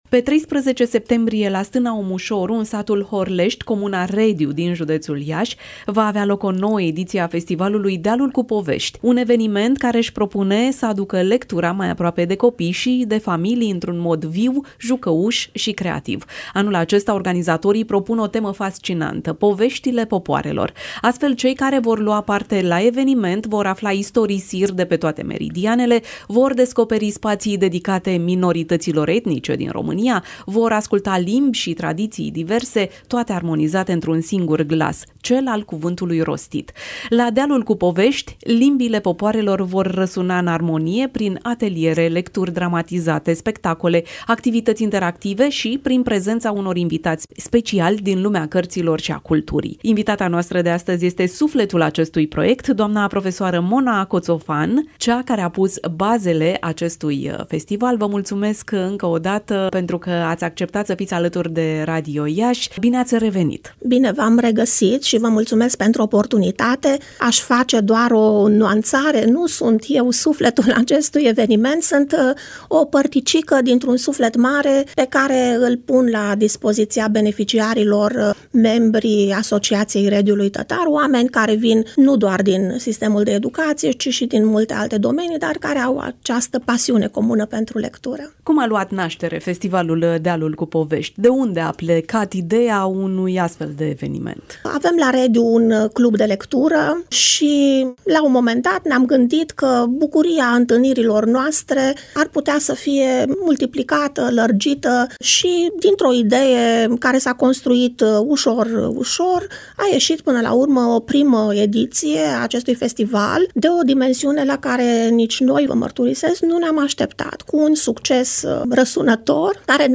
(INTERVIU) „Cititul nu este pedeapsă
Varianta audio a interviului: